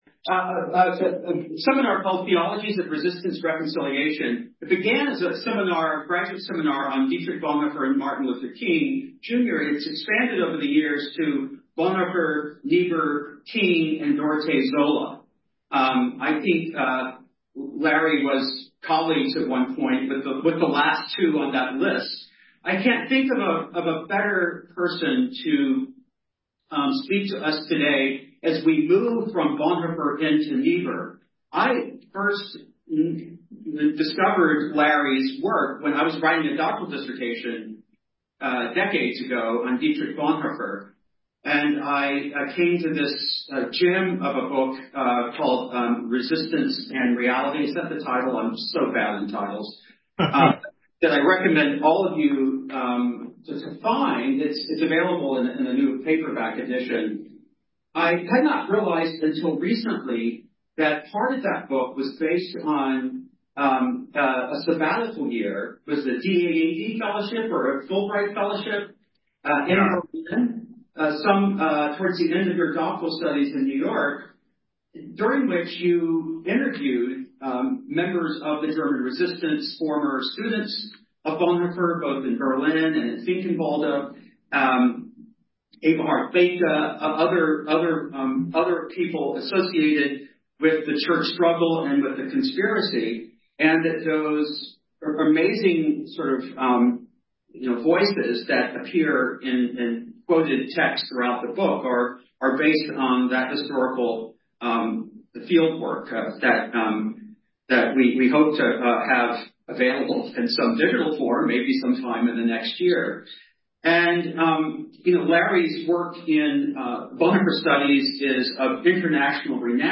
Audio Information Date Recorded: October 8, 2024 Location Recorded: Charlottesville, VA Audio File: Download File » This audio is published by the Project on Lived Theology (PLT).